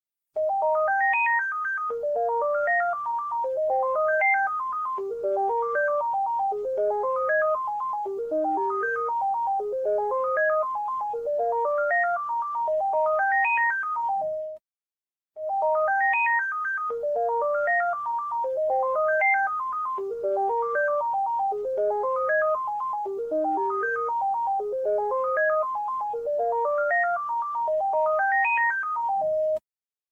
☎ Gigaset Fountain Telefon Klingelton Kostenlos Sound Effects Free Download